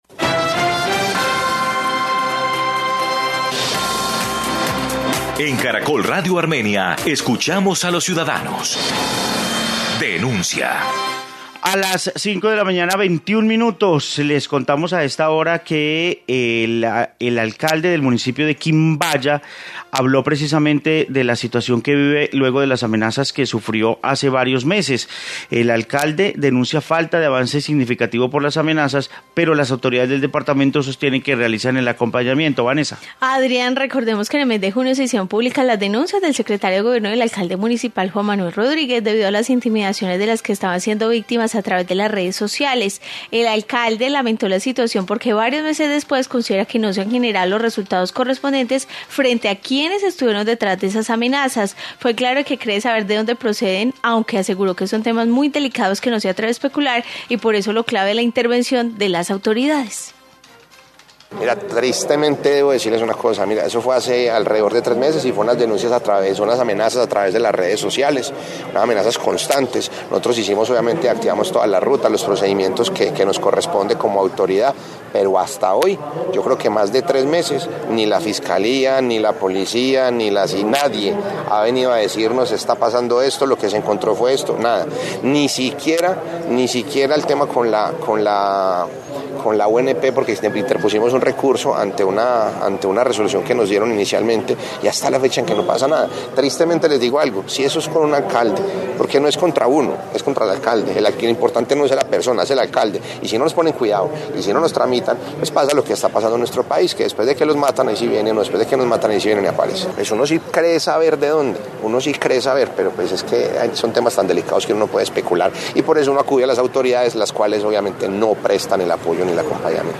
Informe sobre situación de amenazas a alcalde de Quimbaya